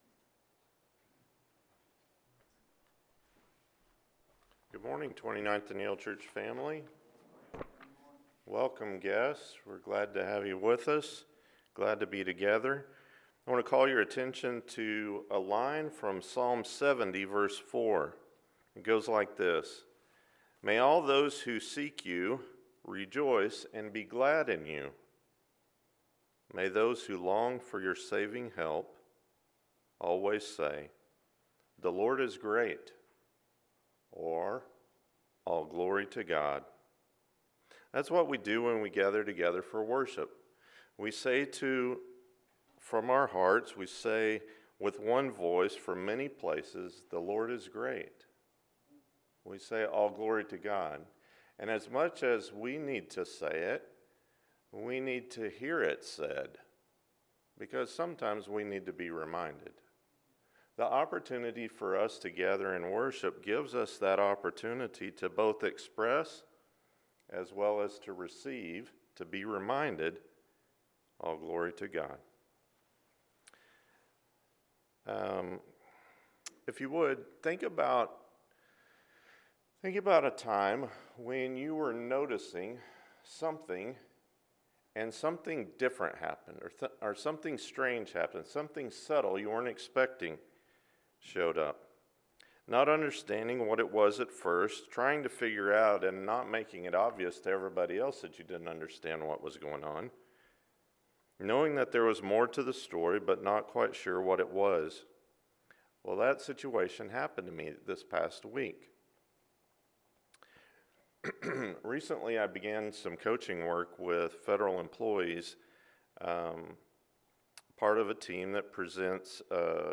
More to the Story: Timothy – I Timothy 6:12-16 – Sermon